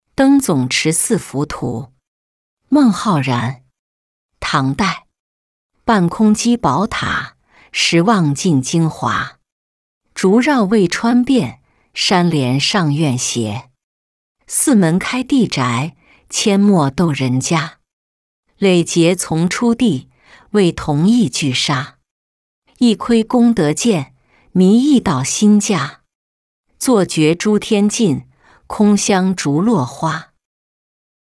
登总持寺浮图-音频朗读